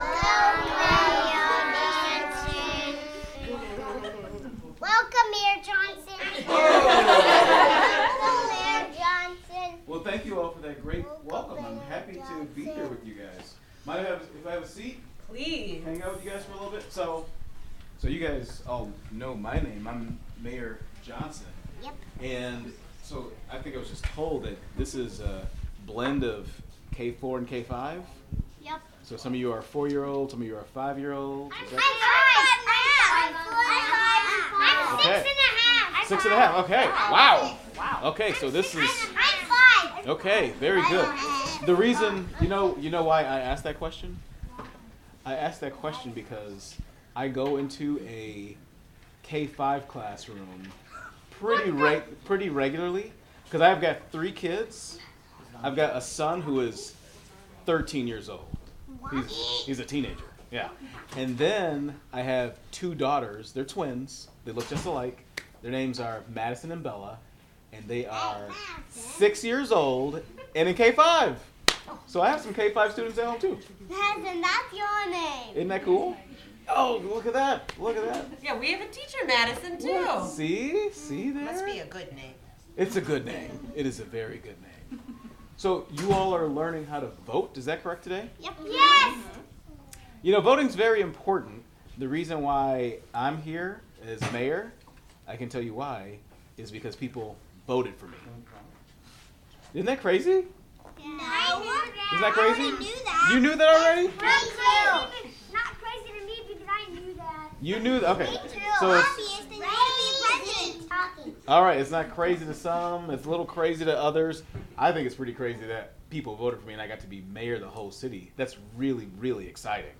Mayor Johnson Interview
Location UWM Children's Learning Center